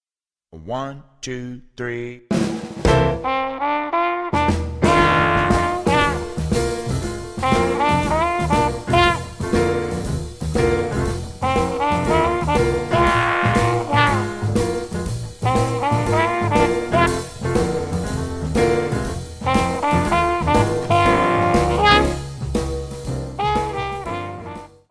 Instruments en Sib, Instruments en Mib